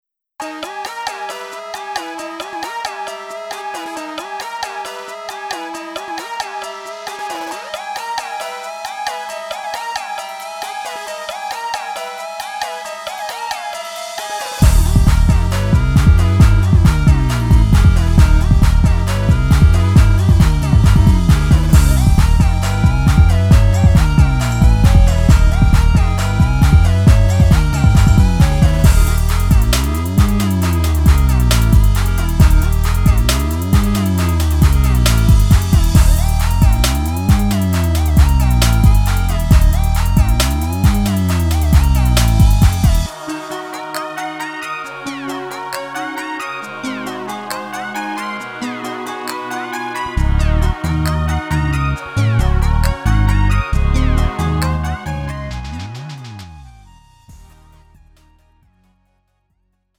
음정 원키 3:39
장르 가요 구분 Lite MR